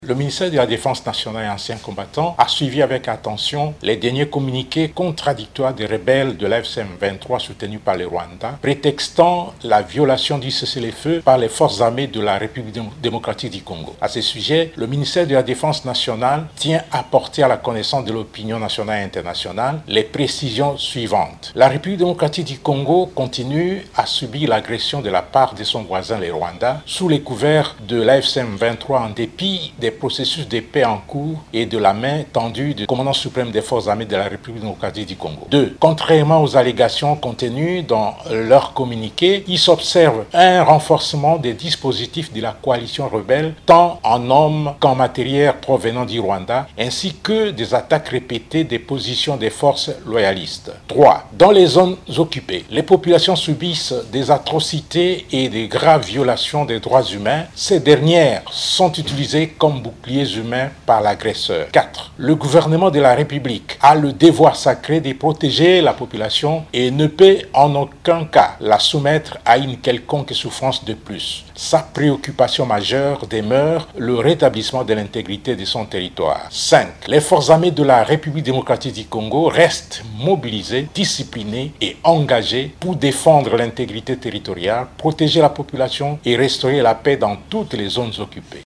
Le porte-parole des FARDC, qui a lu le communiqué du ministère de la Défense, affirme que la RDC continue de subir l’agression du Rwanda. Selon lui, le M23 et l’armée rwandaise renforcent leurs effectifs et leurs matériels dans les zones occupées.
Extrait sonore   du  général major Sylvain EKENGE :